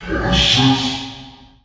Pitched voices soundbank 2
Voices Soundbank